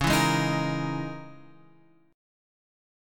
C# Minor 6th Add 9th